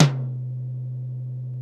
TOM XC.TOM07.wav